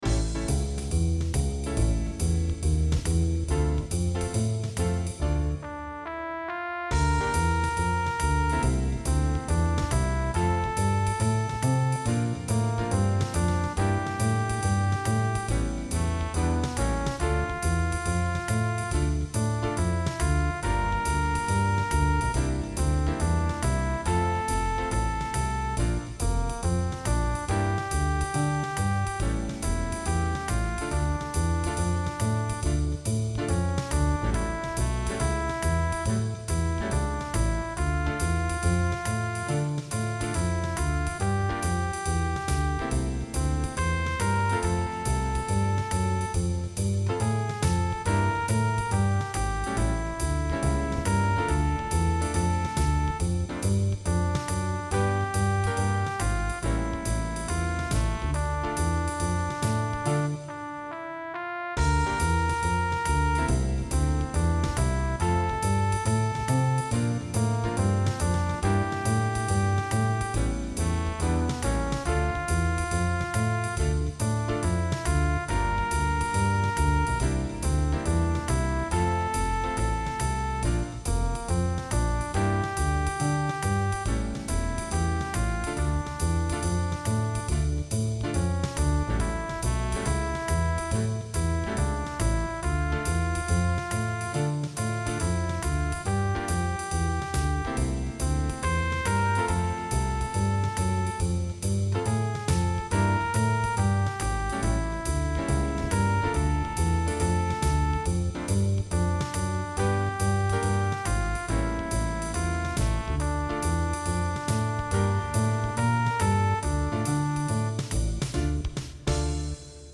TROMBA SOLO • ACCOMPAGNAMENTO BASE MP3
Trombone